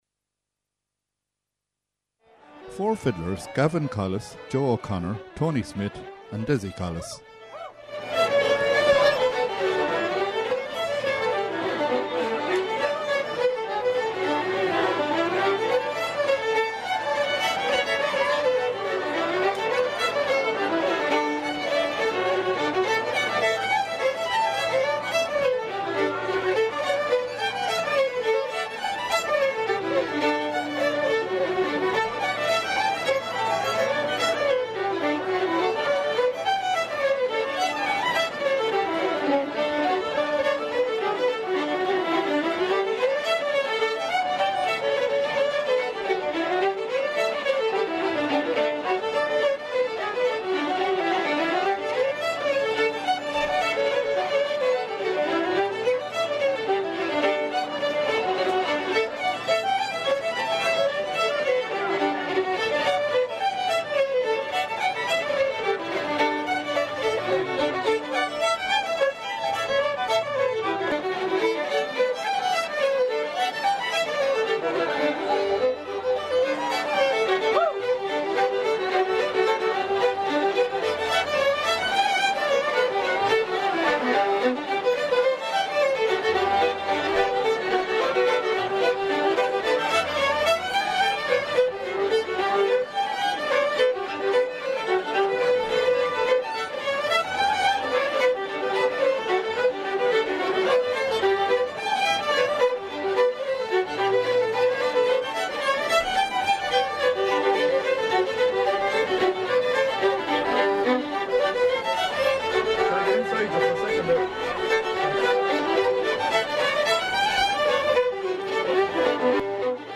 Four fiddle players
play a selection of reels in a late-night street session
Fleadh, Session, Fiddle, Quartet, Reel, ComhaltasLive